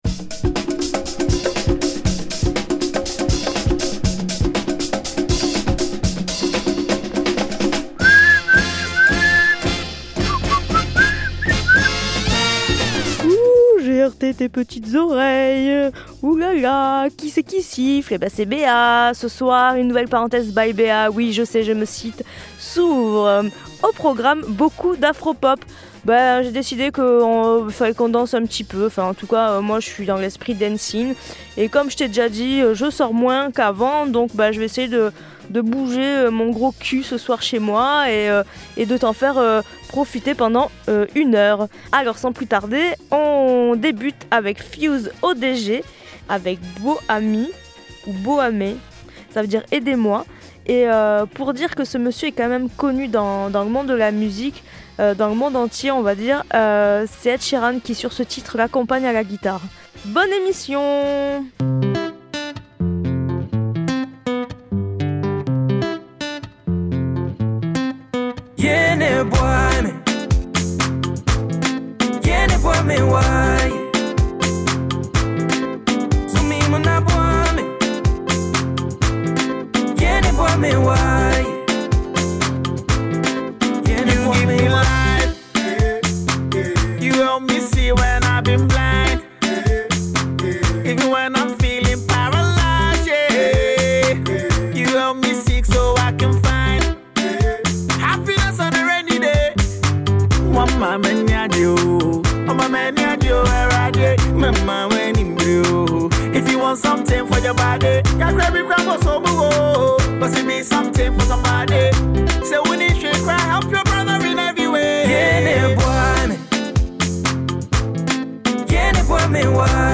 Ce soir, une playlist très afro-pop !